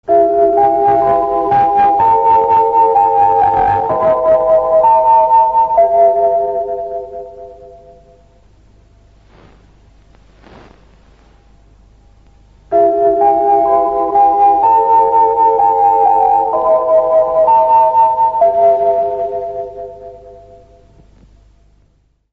Звук позывного